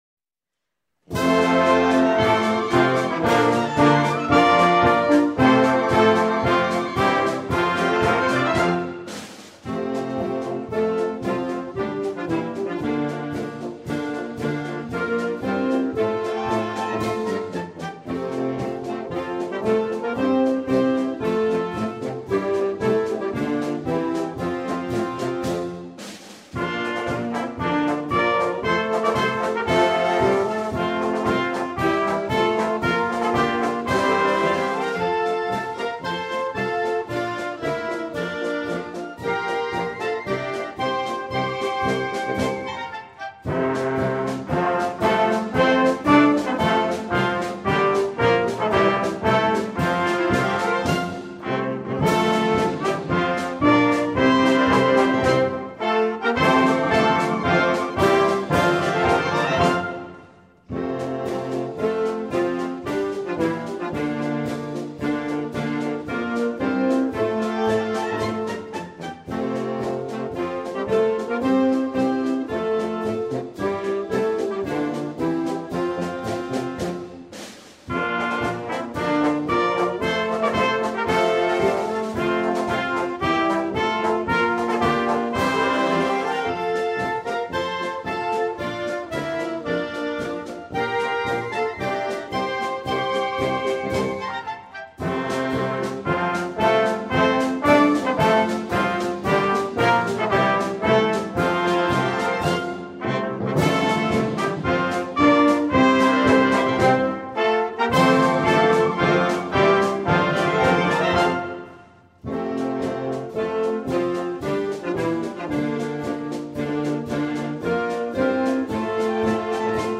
校歌・伴奏のみ
kouka-inst.mp3